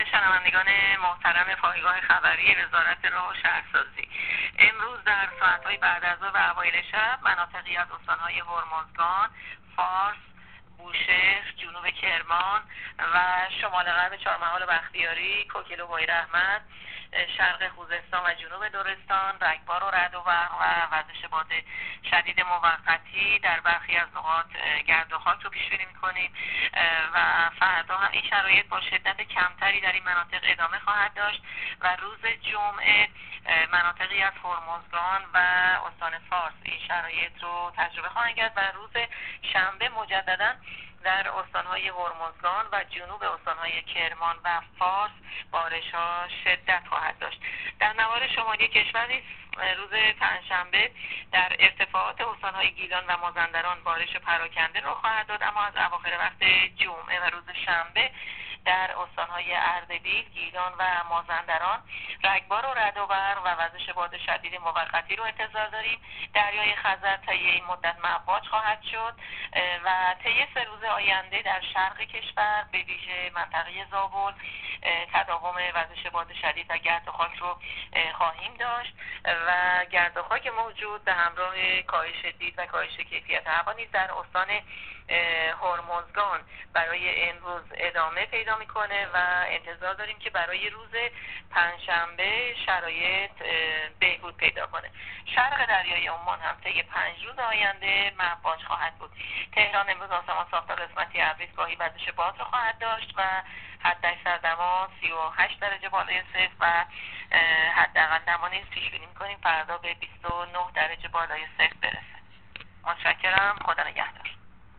کارشناس سازمان هواشناسی کشور در گفت‌وگو با رادیو اینترنتی وزارت راه و شهرسازی، آخرین وضعیت آب‌و‌هوای کشور را تشریح کرد.
گزارش رادیو اینترنتی از آخرین وضعیت آب‌‌و‌‌‌هوای ۳۰ تیر